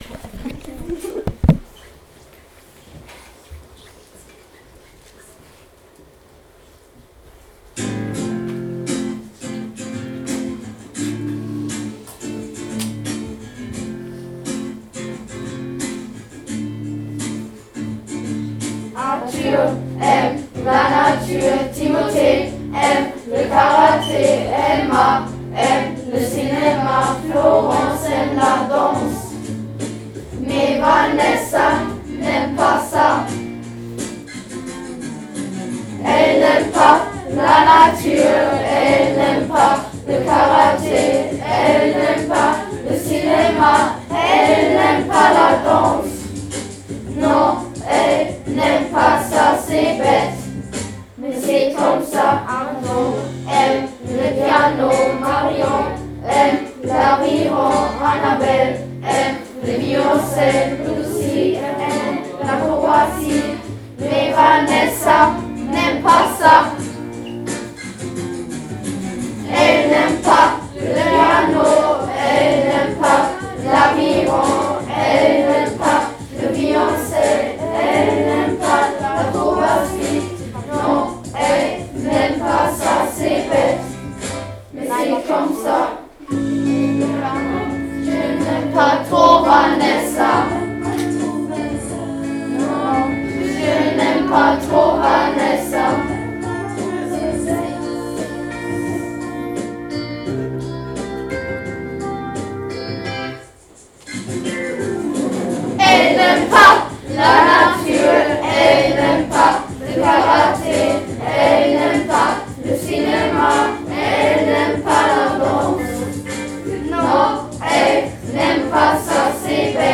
Chanson Vanessa n'aime pas ca Klasse 7G1 7G2.WAV